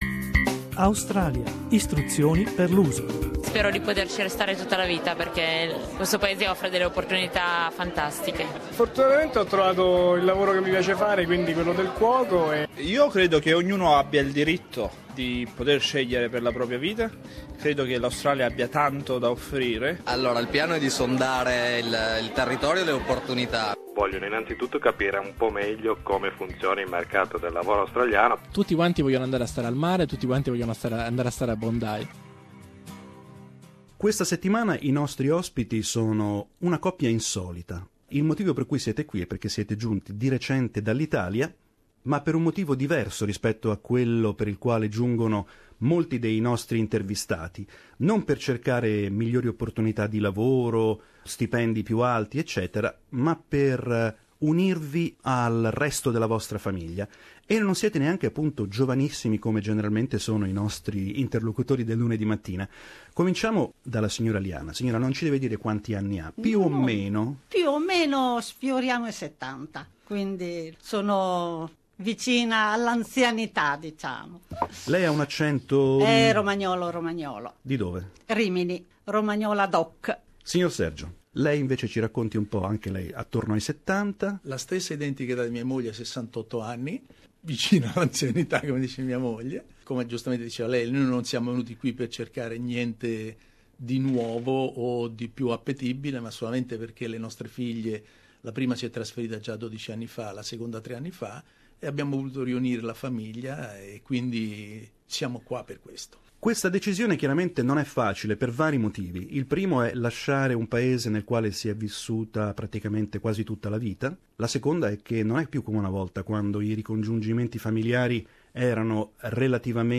Oggi vi riproponiamo due interviste del 2016 che non rientrano nei nostri consueti parametri (ovvero working holiday o student visa, età non superiore ai 30 anni e primi passi nel mondo del lavoro).